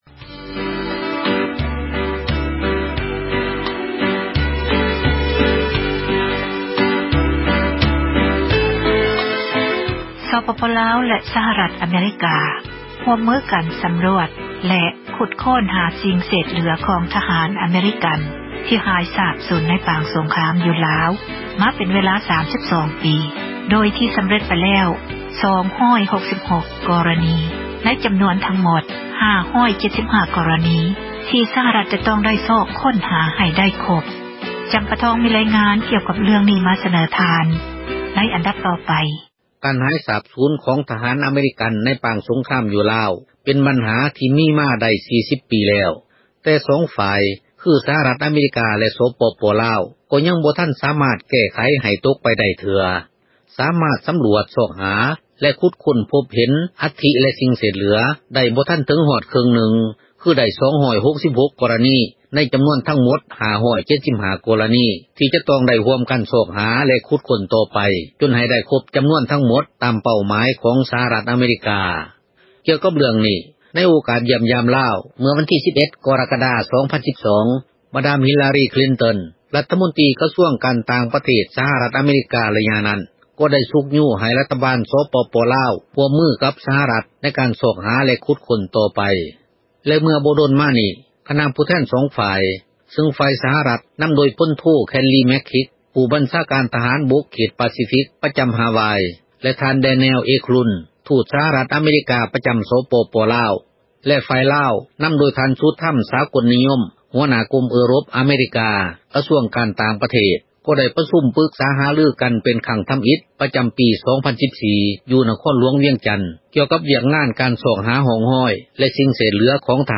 ດັ່ງຊາວລາວ ທ່ານນຶ່ງ ເວົ້າ ໃນວັນທີ 9 ມິນາ 2014 ນີ້ວ່າ: